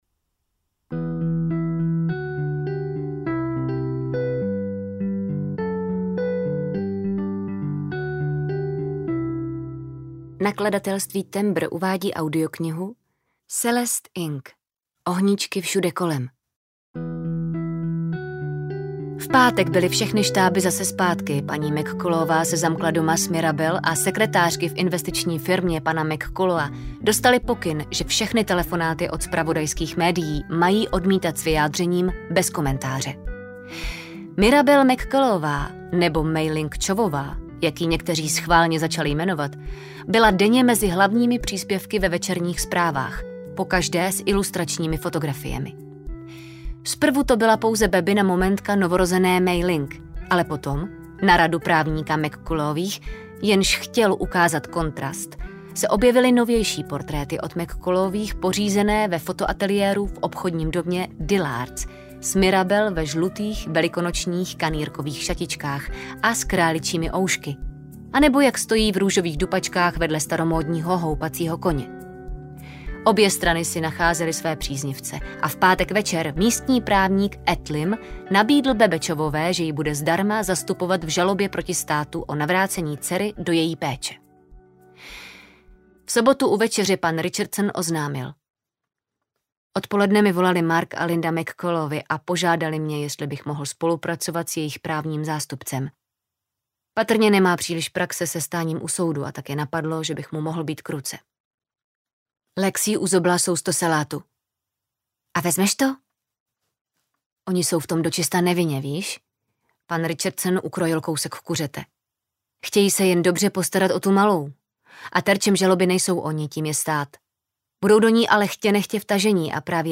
Ohníčky všude kolem audiokniha
Ukázka z knihy